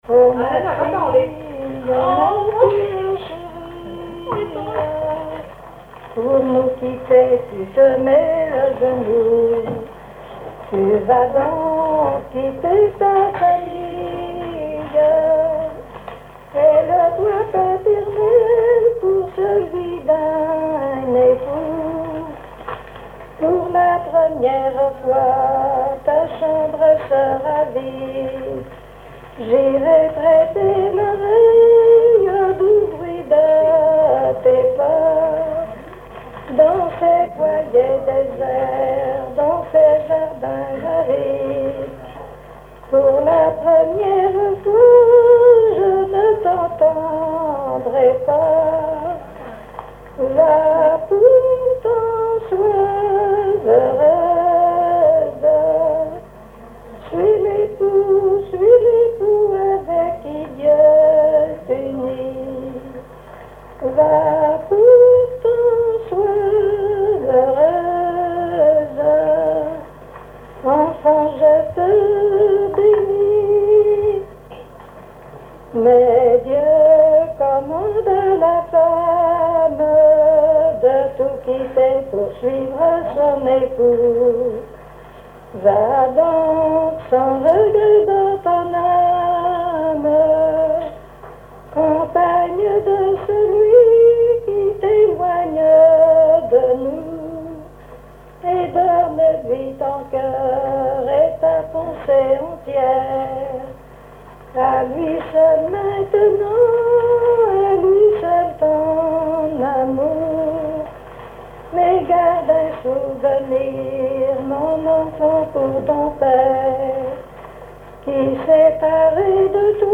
circonstance : fiançaille, noce
Genre strophique
collecte en Vendée
Pièce musicale inédite